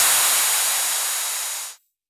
RDM_Copicat_SY1-OpHat.wav